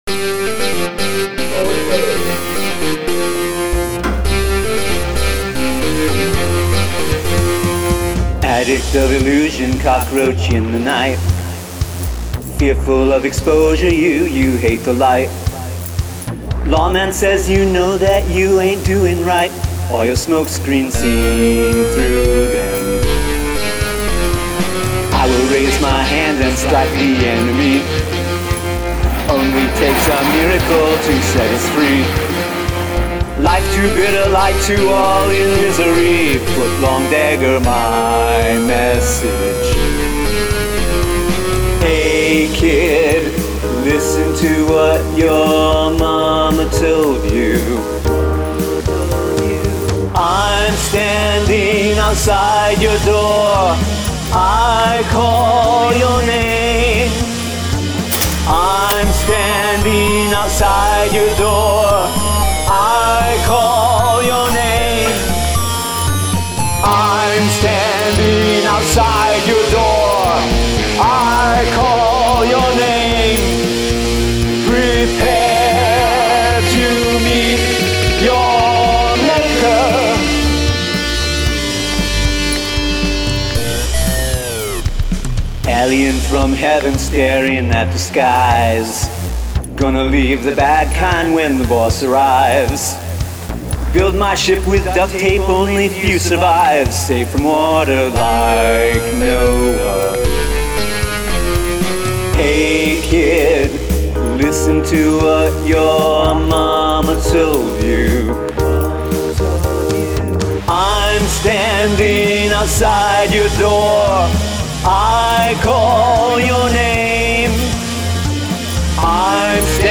Ambience
I really like the (synth?) lead guitar work on this one.